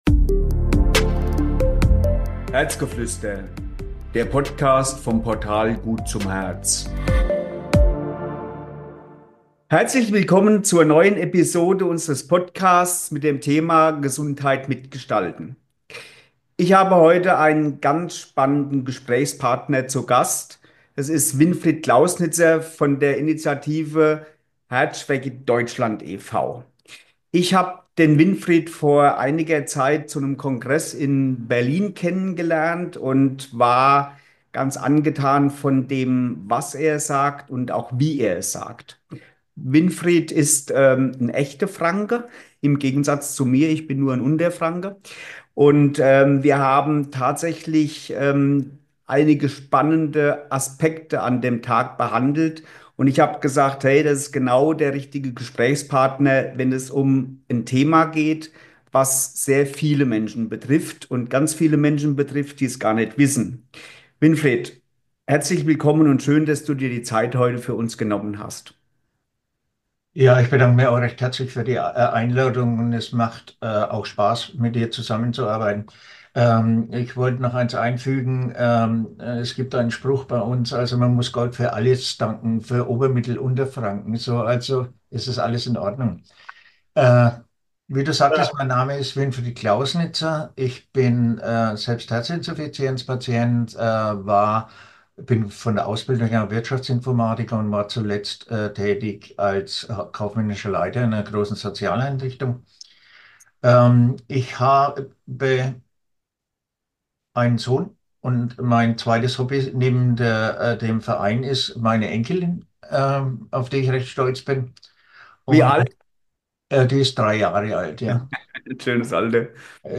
Ein eindrucksvolles Gespräch über Eigeninitiative, Gemeinschaft und die Möglichkeiten, Gesundheit aktiv mitzugestalten.